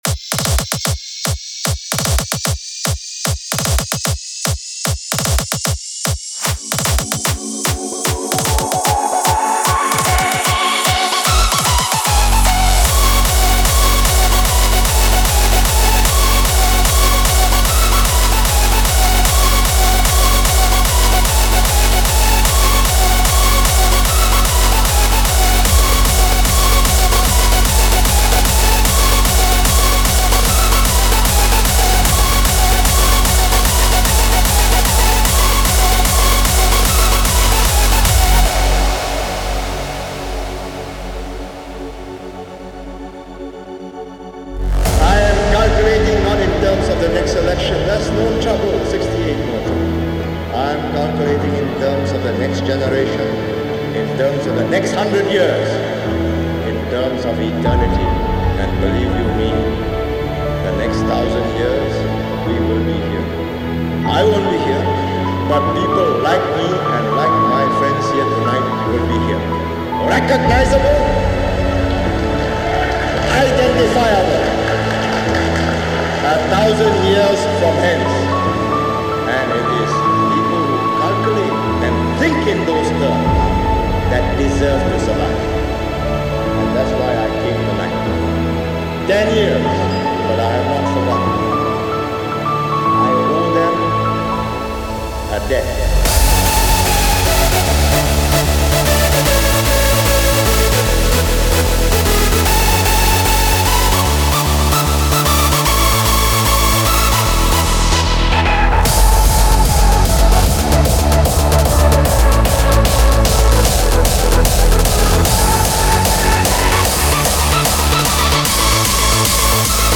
Presidential hardstyle?
Dance